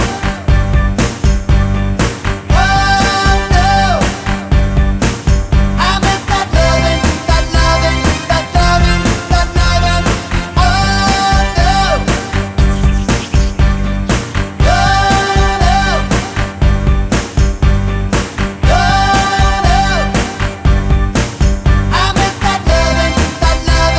no rap R'n'B / Hip Hop 3:57 Buy £1.50